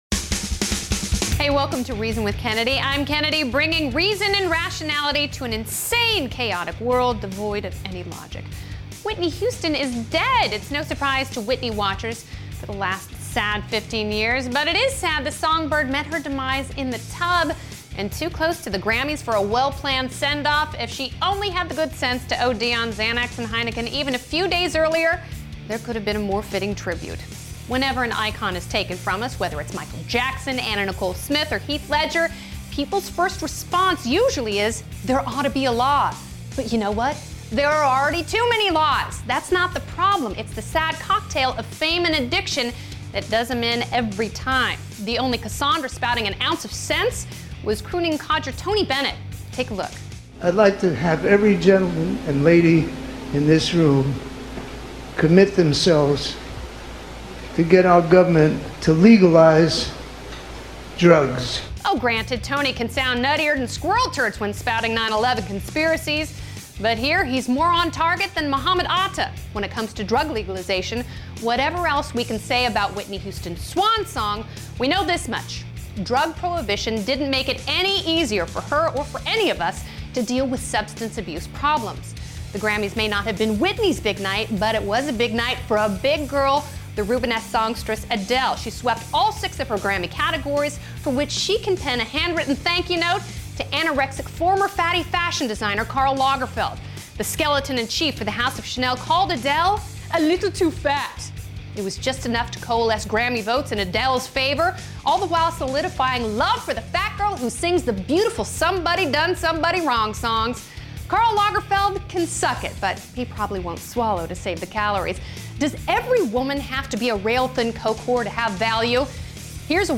Written by Nick Gillespie and Kennedy, who also hosts.